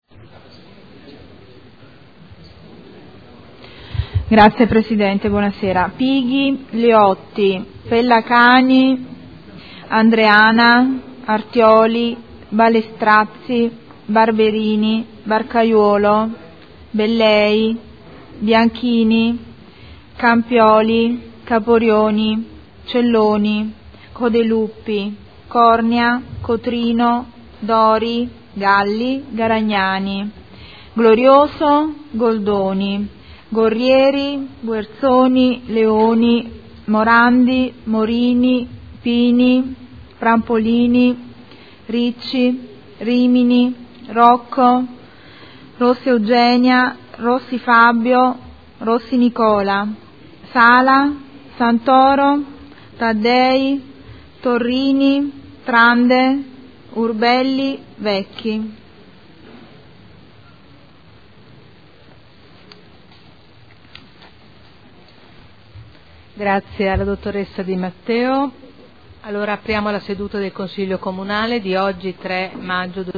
Seduta del 03/05/2012. Appello